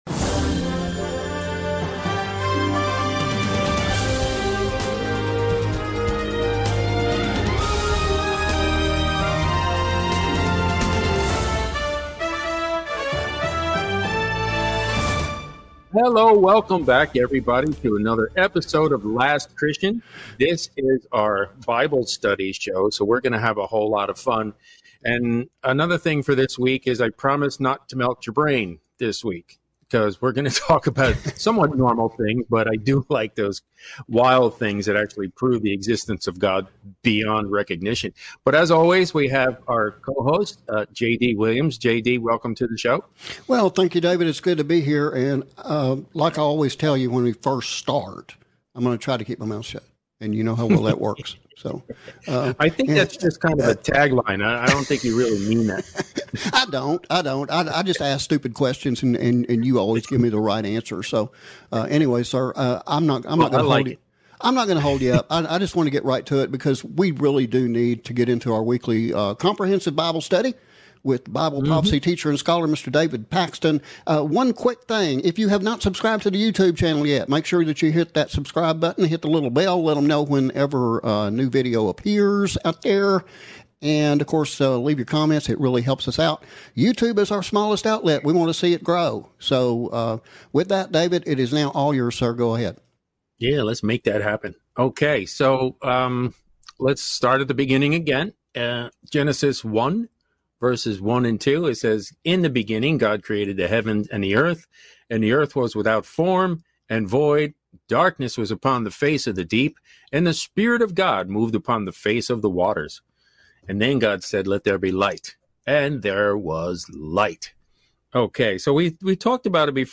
Comprehensive Bible Study